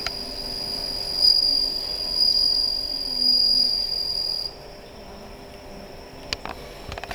西方小にいるスズムシ
このスズムシが朝と夕方、きれいな鳴き声を響かせていて、秋の訪れを感じることができます。
スズムシの鳴き声.wav